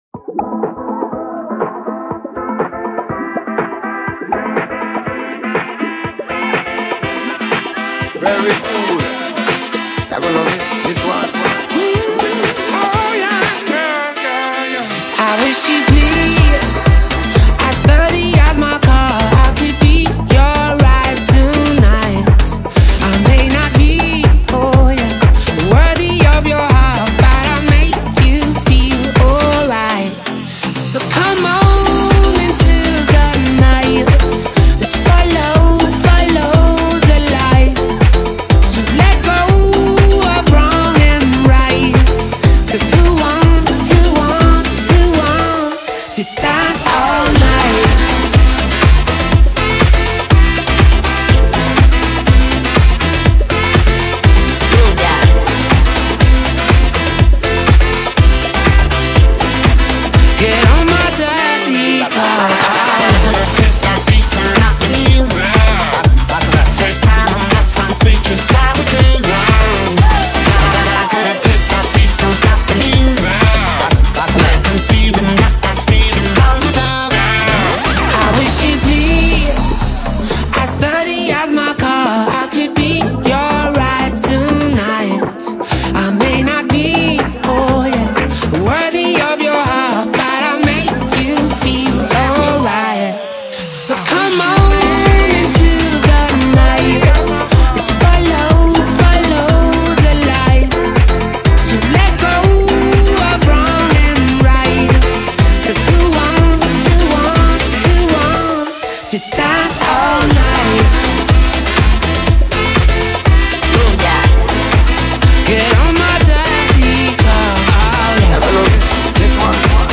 BPM122
Comments[ELECTRO HOUSE]